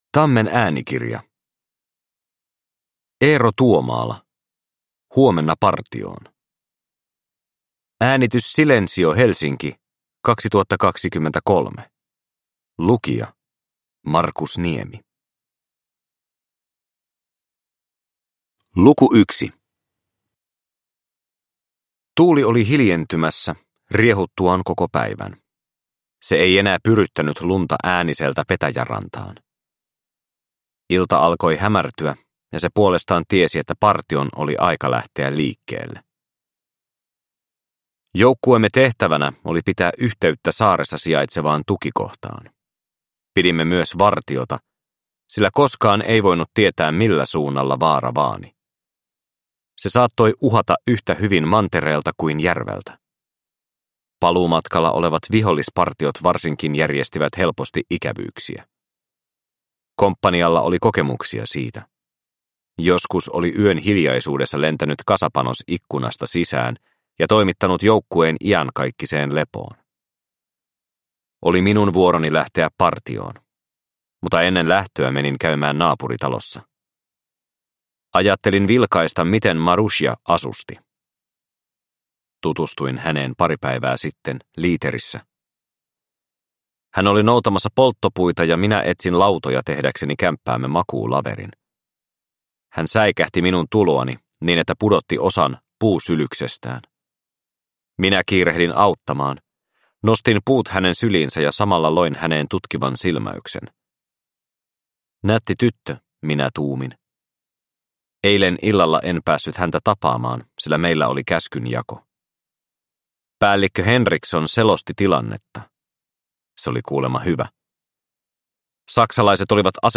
Huomenna partioon – Ljudbok